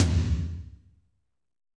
TOM F RLO19R.wav